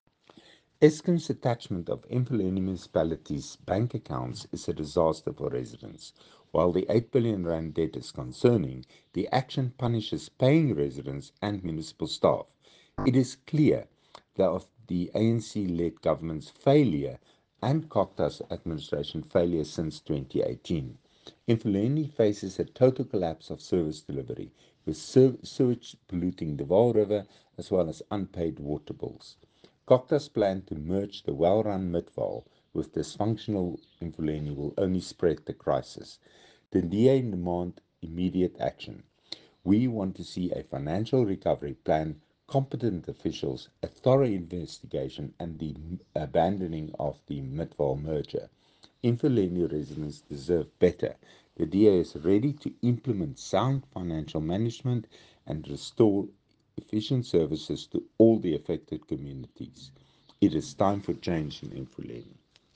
Note to Editors: Please find an English soundbite from Nico De Jager MPL